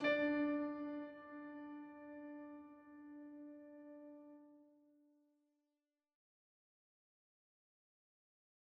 Keyboard - Latin Jam.wav